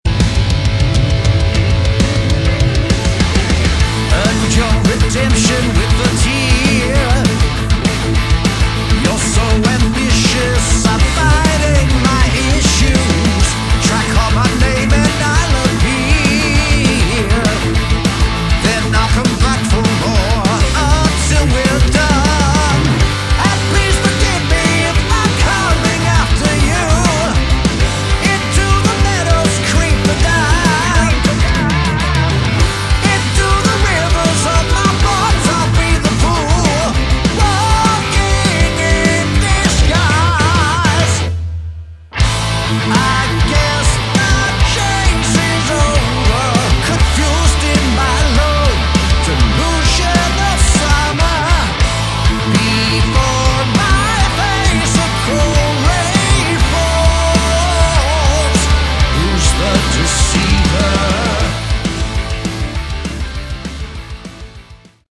Category: Melodic Metal / Prog Metal
guitars, bass
drums
keyboards